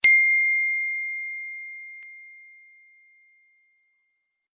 Iphone Notification Sound Button: Meme Soundboard Unblocked
Iphone Notification